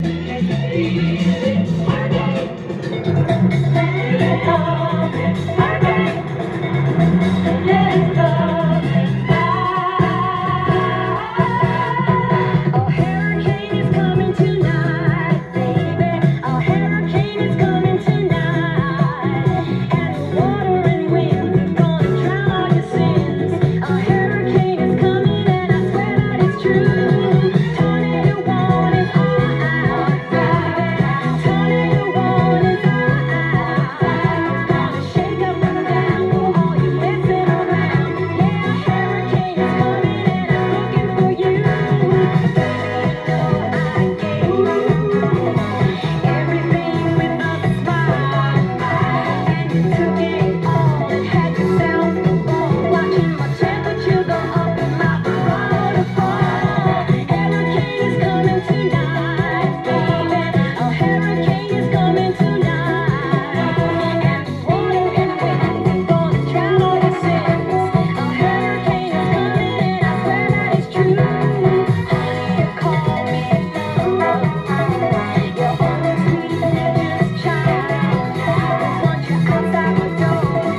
ジャンル：Soul-7inch-全商品250円
店頭で録音した音源の為、多少の外部音や音質の悪さはございますが、サンプルとしてご視聴ください。